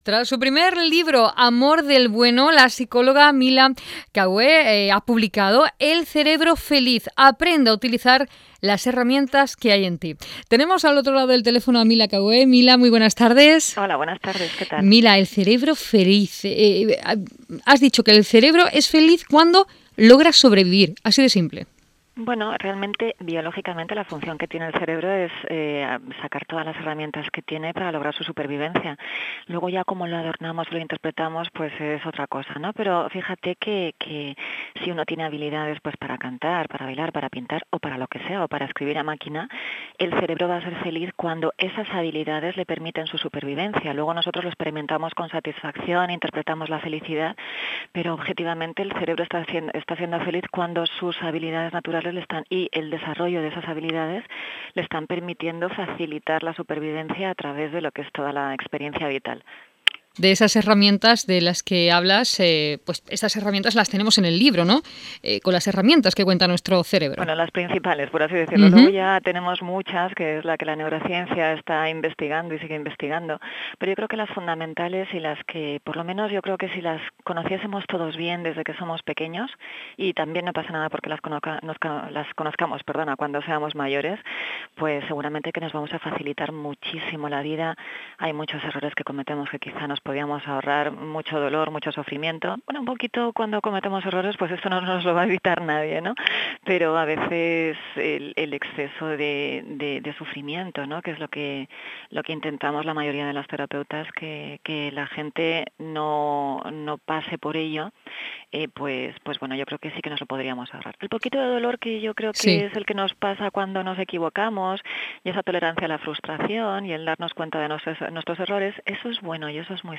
Aquí os dejo una estupenda entrevista que mantuve hace unos días en Onda Cero Cádiz.